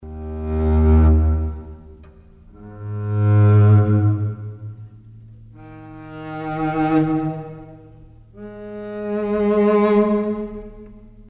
Ecoutez un archet contrebasse